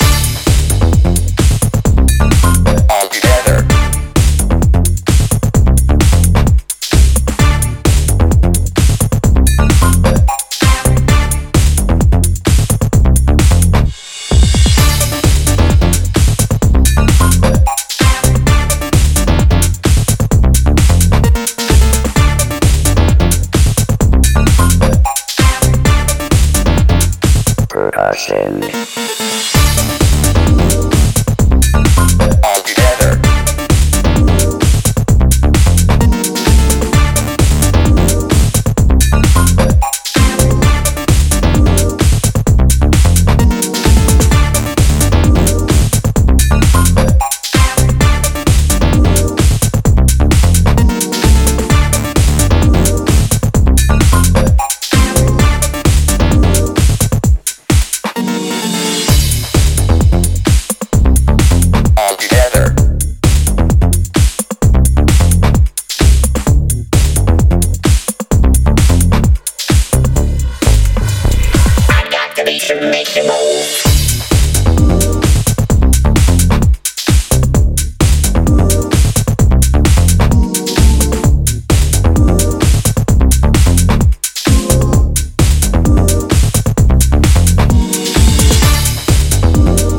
A monster bassline, punchy drums and dreamy pads make